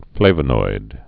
(flāvə-noid)